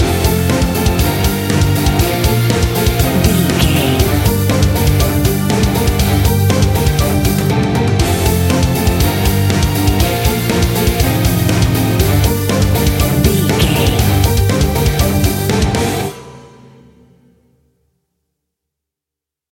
Epic / Action
Fast paced
Aeolian/Minor
hard rock
guitars
horror rock
Heavy Metal Guitars
Metal Drums
Heavy Bass Guitars